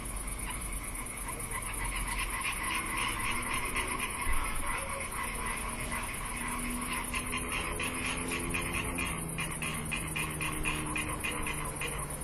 Laubfrosch, Herbstrufe
Laubfrosch: Herbstrufe Hier ein 12 Sekunden langer Ausschnitt mit einigen Herbstrufe am 12.9.2022.